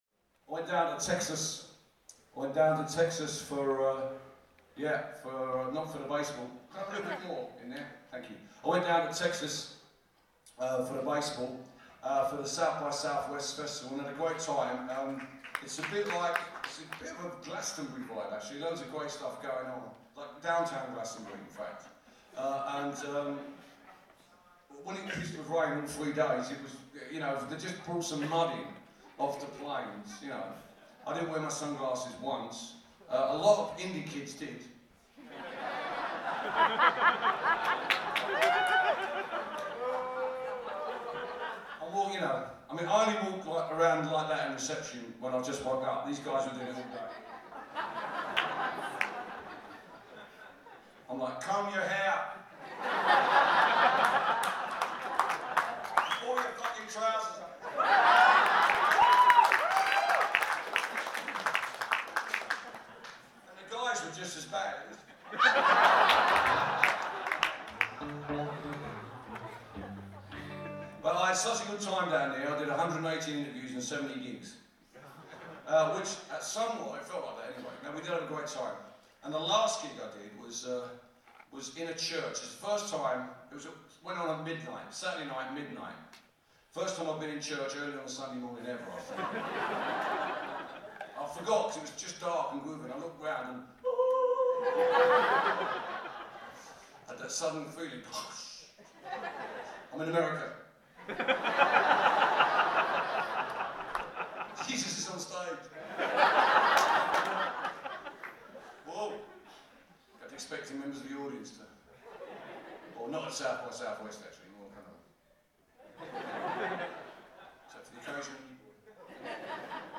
Billy Bragg came back to the Somerville Theatre on his ‘Hope Not Hate‘ tour last Thursday… just the man, his guitar, and a well-worn voice that registered a little lower than usual.
A two hour show with maybe 45 minutes of top-notch ramblin’, ranging from the usual subjects (politics, women, and Woody Guthrie) to the not-so-usual (indie kid fasion at SXSW, curling, and King Kong’s anatomical incorrectness).
Live at the Somerville Theatre
05-BillyBragg-ramble2(live).mp3